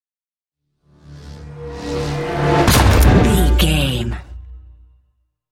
Horror whoosh to hit 644
Sound Effects
In-crescendo
Atonal
scary
ominous
eerie
woosh to hit